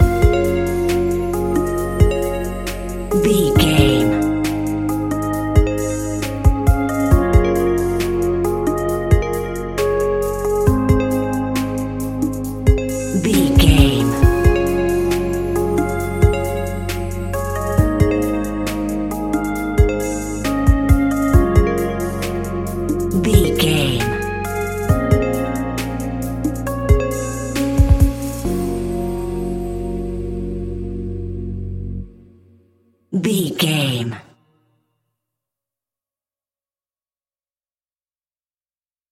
Aeolian/Minor
B♭
Slow
Elecronica Music
laid back
groove
hip hop drums
hip hop synths
piano
hip hop pads